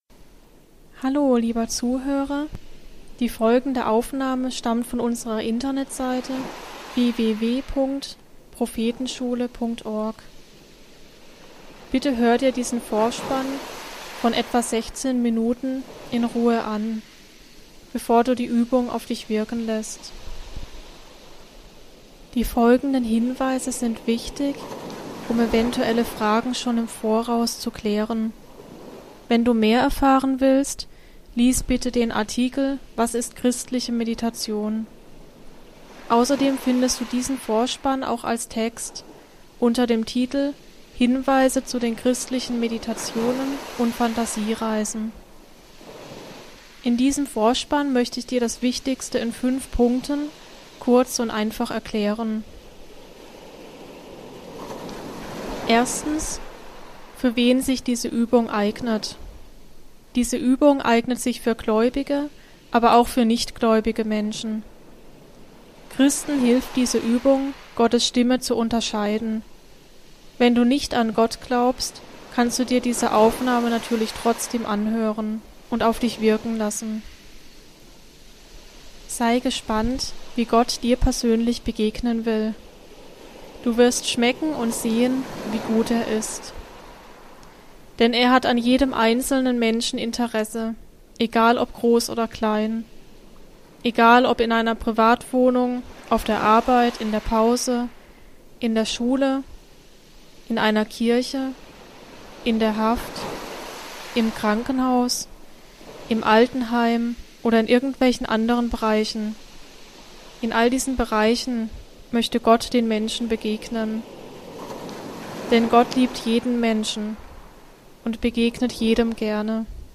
Ein Spaziergang im Wald (Meditation, Phantasiereise/Fantasiereise)